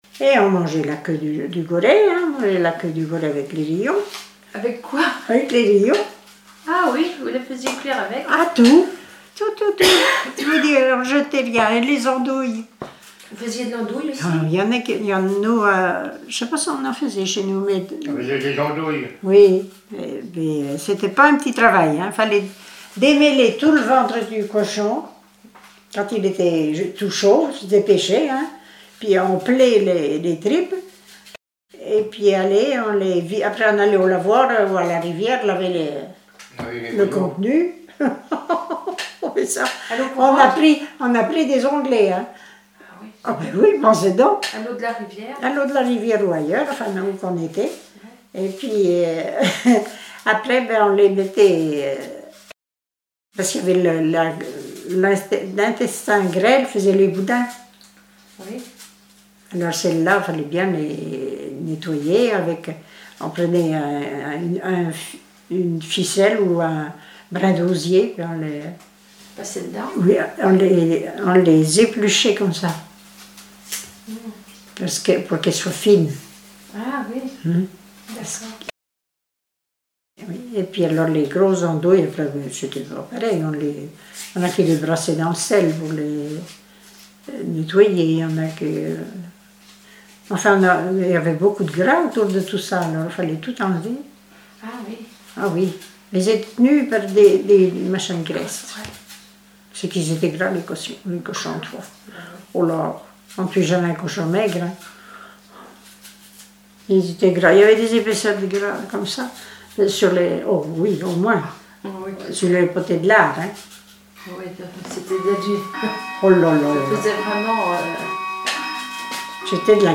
Témoignages sur les tâches ménagères
Catégorie Témoignage